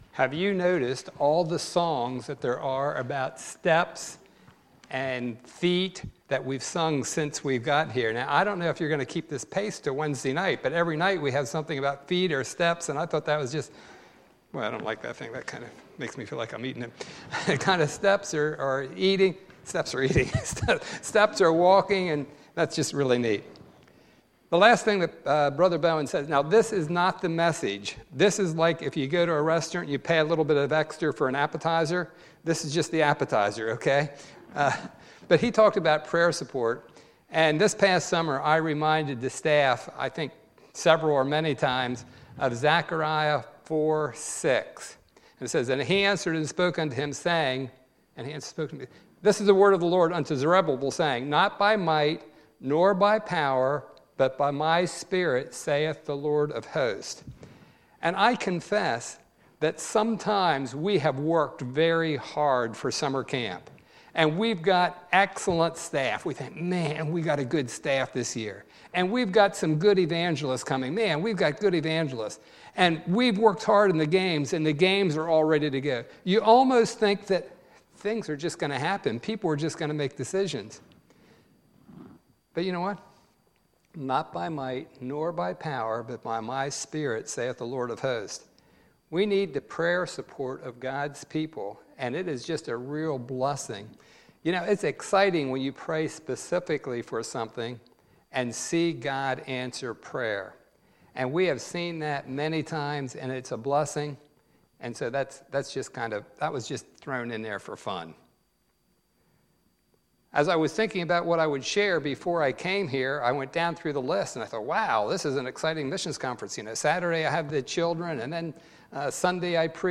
Monday, September 24, 2018 – Monday Evening Service